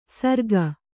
♪ sarge